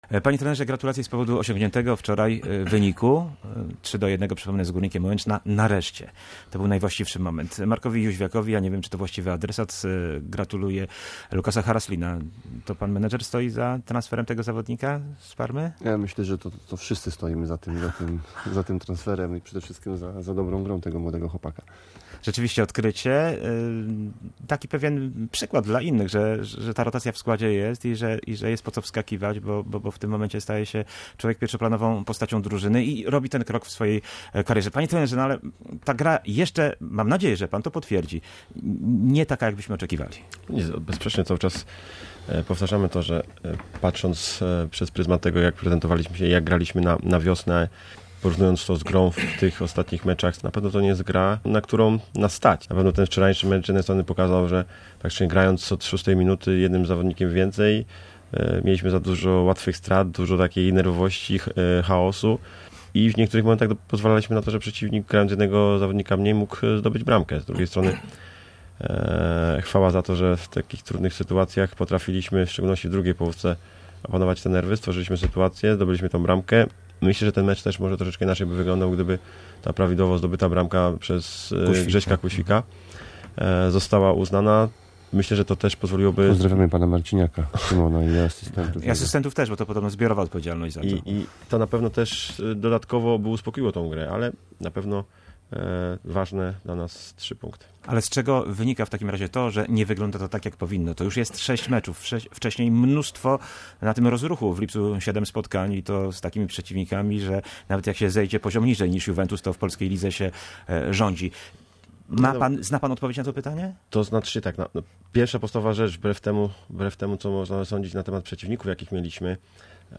Lechia wyjątkowa pod każdym względem – biało-zielona debata w Radiu Gdańsk